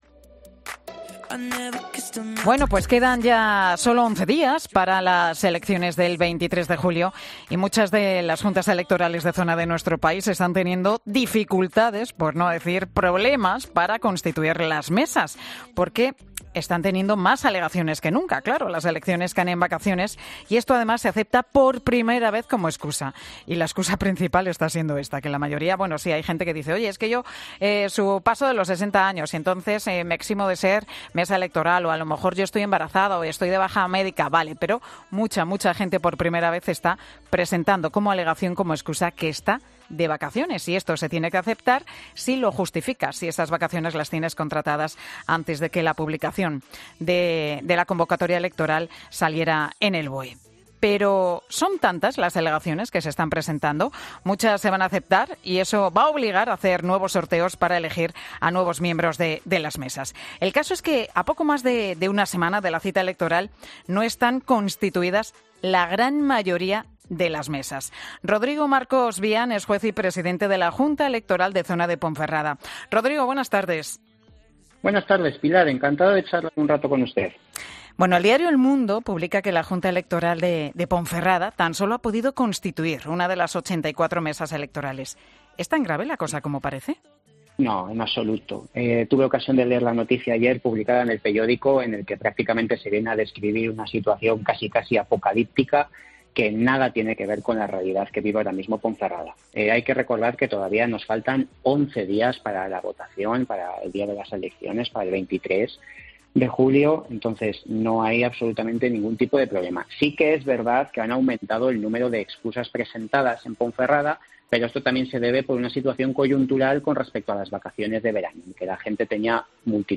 El presidente de la Junta Electoral de Zona ha asegurado en 'Mediodía COPE' que "han aumentado el número de alegaciones por el verano"